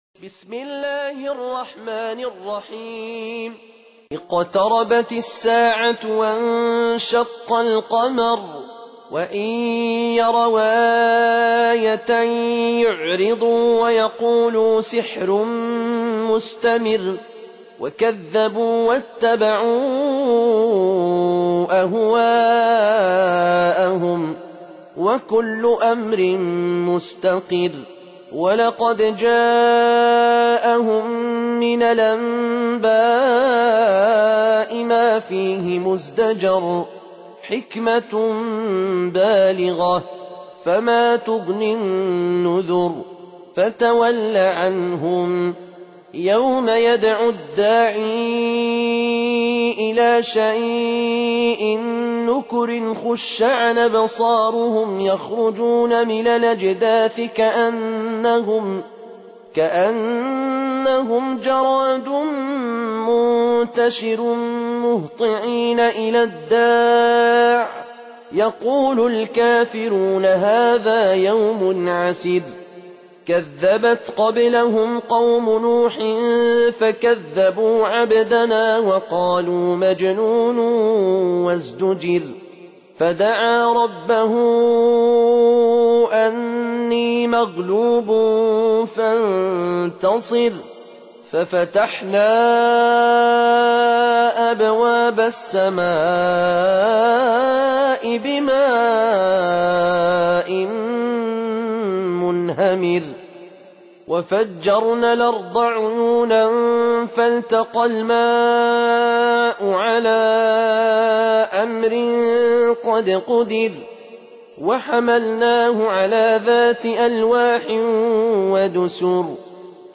54. Surah Al-Qamar سورة القمر Audio Quran Tarteel Recitation
Surah Repeating تكرار السورة Download Surah حمّل السورة Reciting Murattalah Audio for 54.